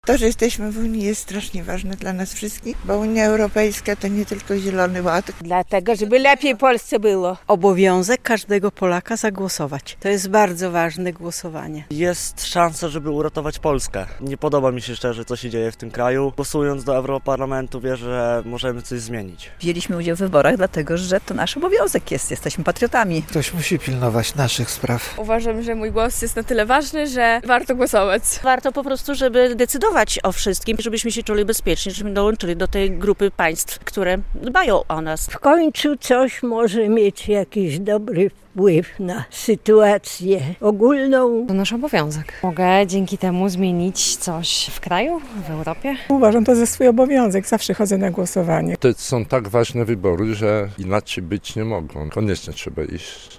Głosujący białostoczanie twierdzą, że wybory do Parlamentu Europejskiego są bardzo ważne - relacja